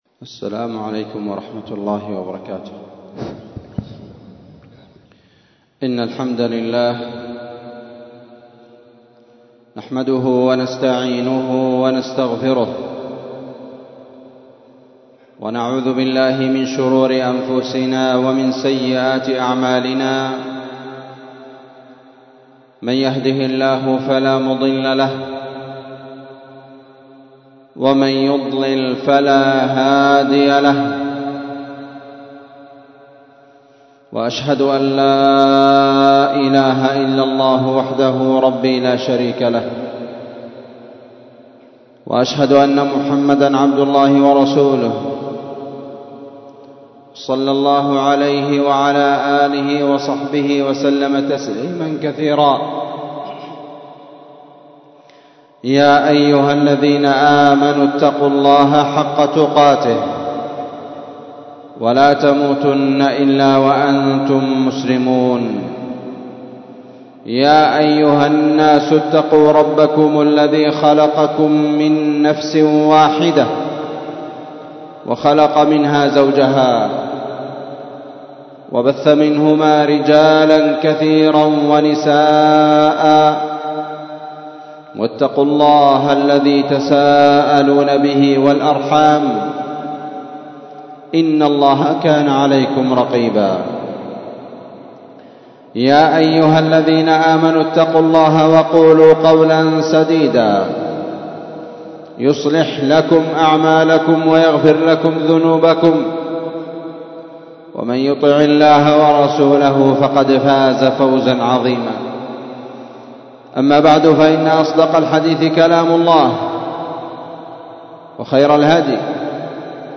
*خطبة الجمعة* ????????
مسجد المجاهد- النسيرية- تعز تحميل تحميل إستماع